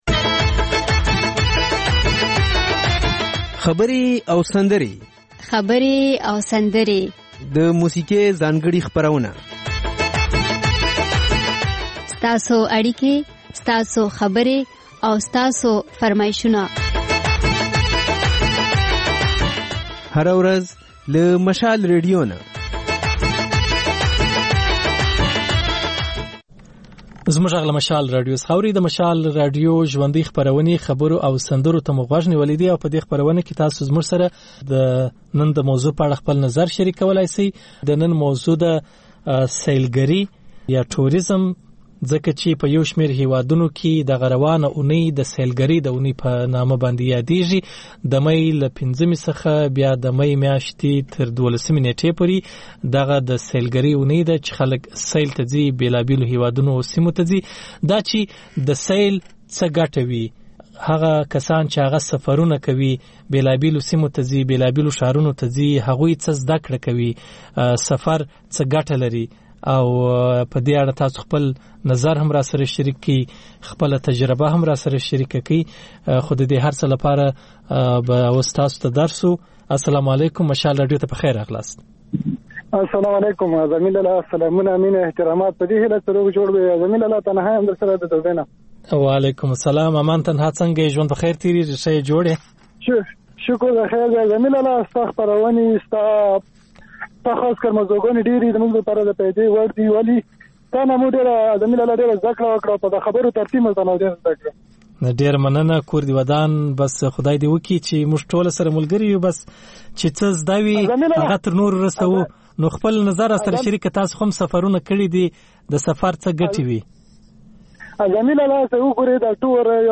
په دې خپرونه کې له اورېدونکو سره خبرې کېږي، د هغوی پیغامونه خپرېږي او د هغوی د سندرو فرمایشونه پوره کېږي. دا یو ساعته خپرونه د پېښور پر وخت سهار پر څلور او د کابل پر درې نیمو بجو تکرار خپرېږي.